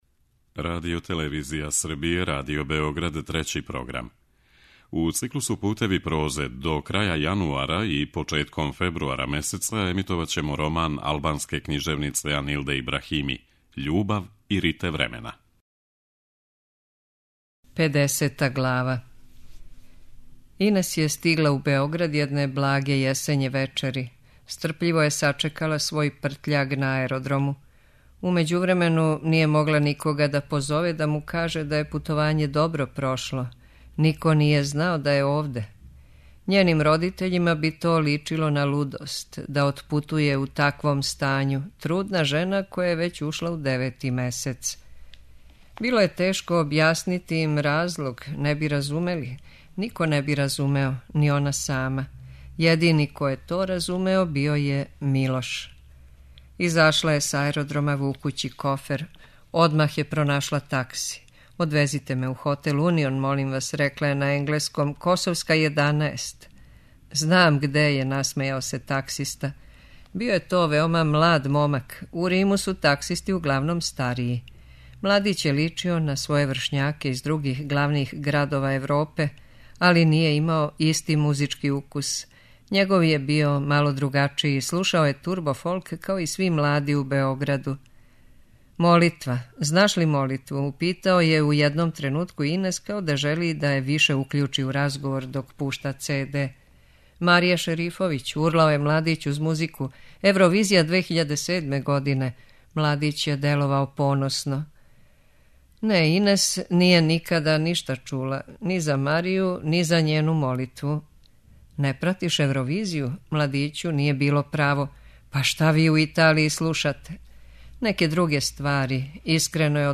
Књига за слушање: Анилда Ибрахими: Љубав и рите времена (26)